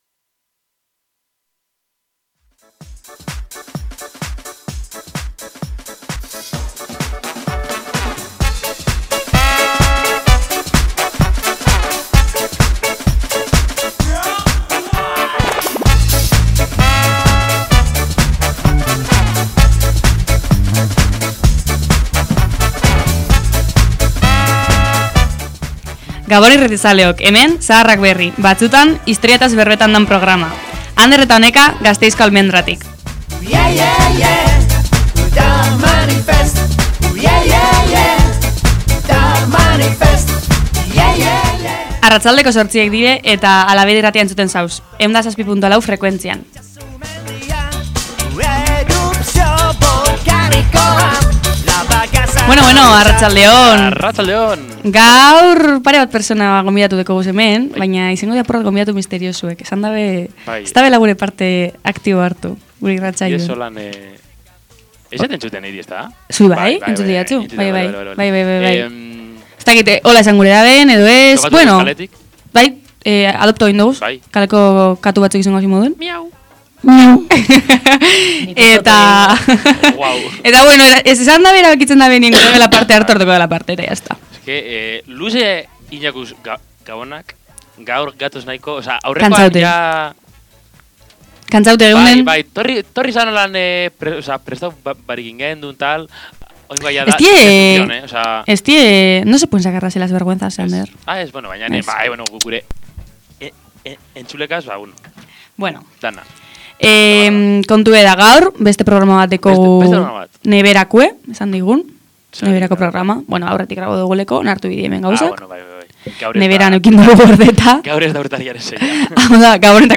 Aquí tenéis la entrevista: Suelta la olla: Memoria y reparación de víctimas de violencias machistas 00:50:27 10 0 1 Hala Bedi babestu nahi duzu?